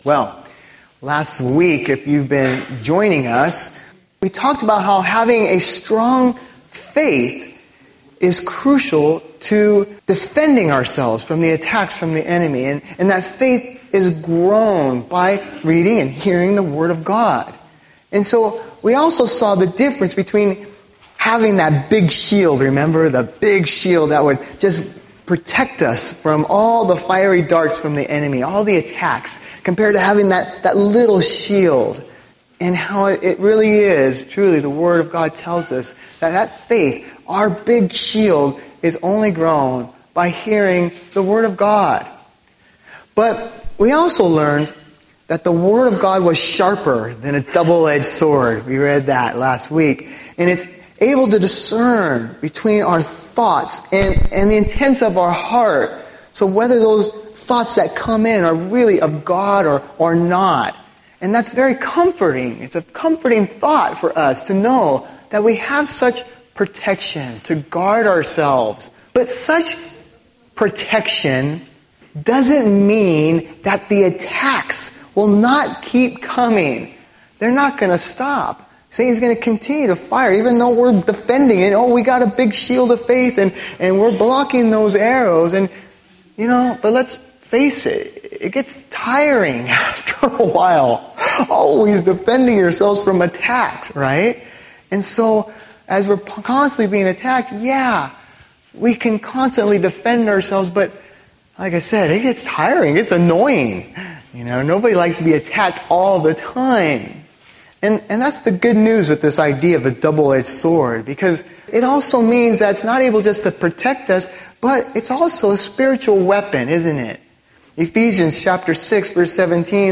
Audio recordings, transcripts and Youtube link of Bible messages shared at OIC.